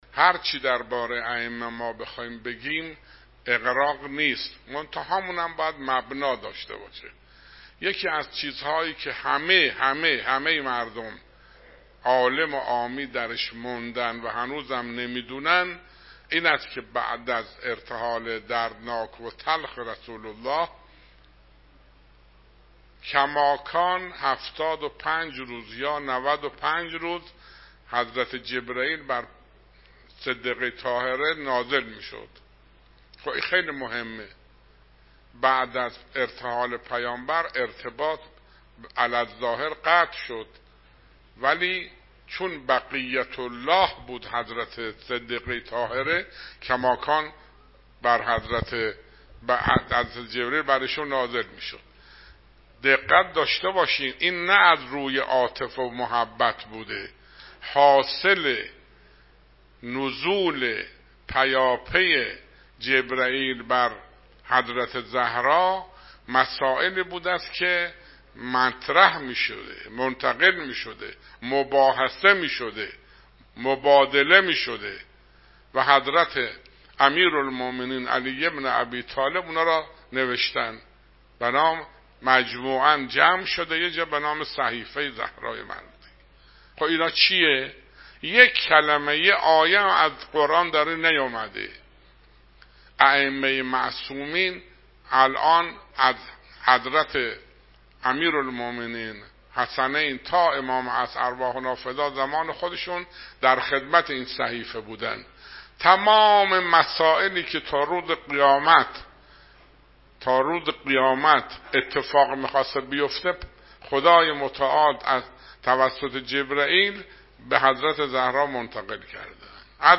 به گزارش خبرنگار خبرگزاری رسا، محمدعلی بشارتی جهرمی مشاور رییس مجمع تشخیص مصلحت نظام، شب گذشته در مسجدجامع غدیرخم با موضوع انقلاب اسلامی به ایراد سخنرانی پرداخت و گفت: ظهور عام اتفاق افتاده و امروز در شرف رسیدن به ظهور خاص هستیم.